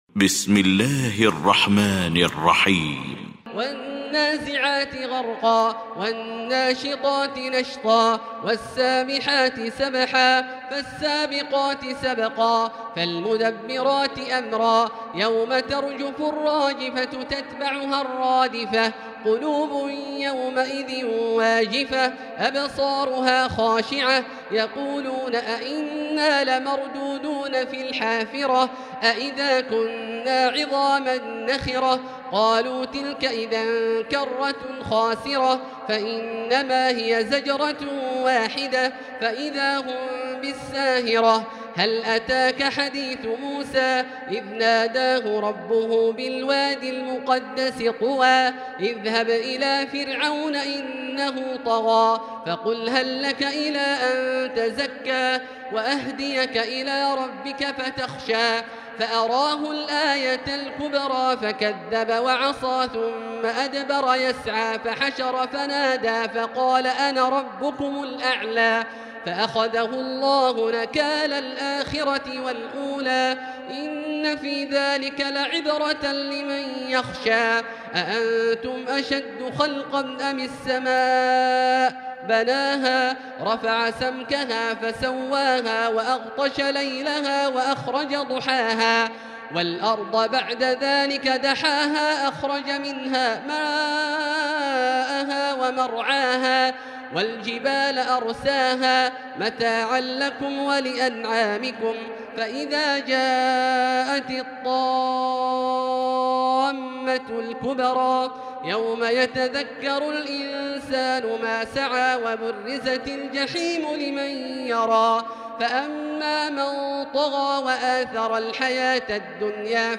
المكان: المسجد الحرام الشيخ: فضيلة الشيخ عبدالله الجهني فضيلة الشيخ عبدالله الجهني النازعات